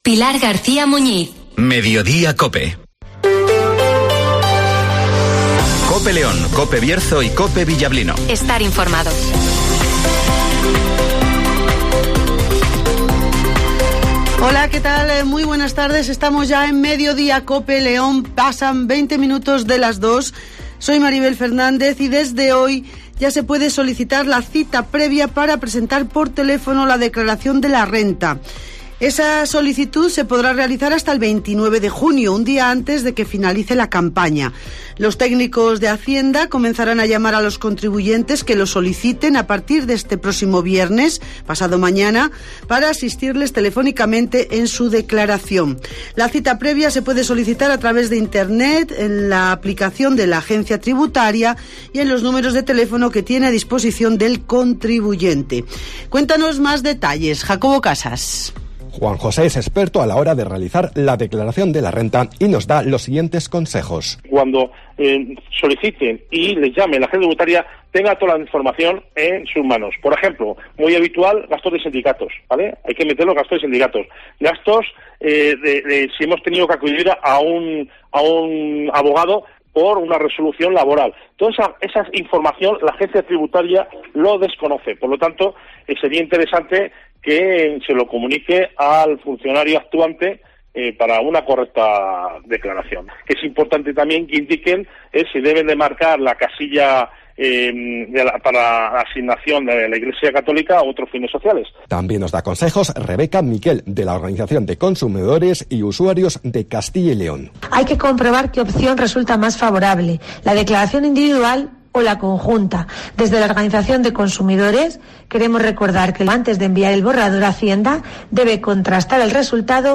Escucha aquí las noticias con las voces de los protagonistas.